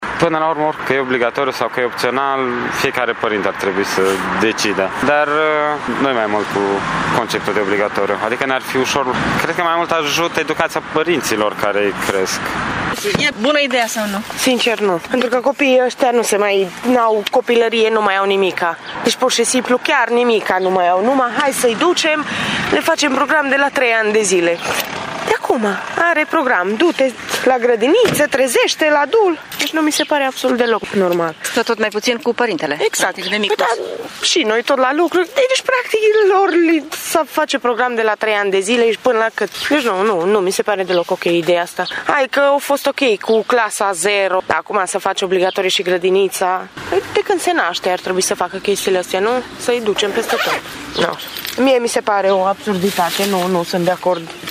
Măsura nu îi încântă deloc pe unii părinți târgumureșeni care se simt frustrați de faptul că statul vrea, prin diverse mijloace, să țină copiii departe de părinți: